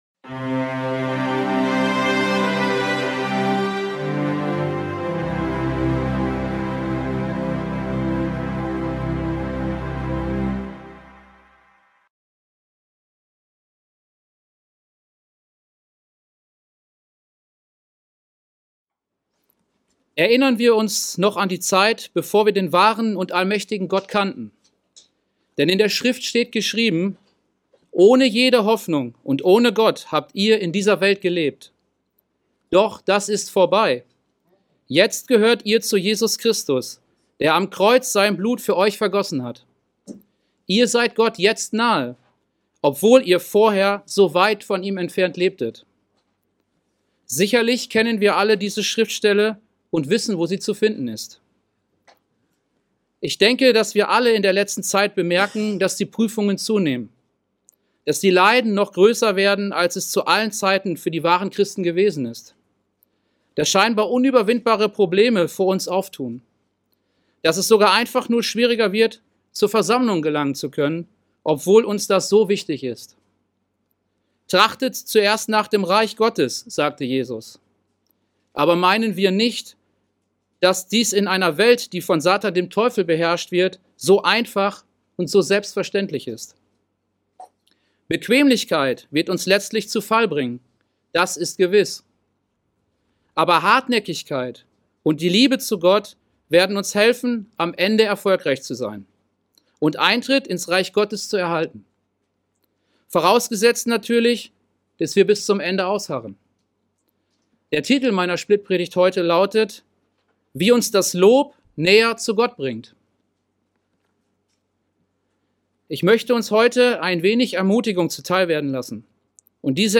In dieser Splitpredigt wird aufgezeigt, dass wir Gott loben müssen.